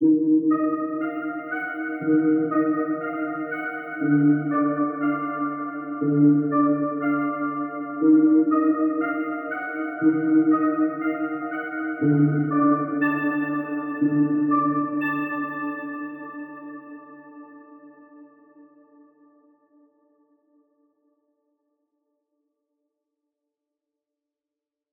AV_Sneaky_Bell_60bpm_C#min
AV_Sneaky_Bell_60bpm_Cmin.wav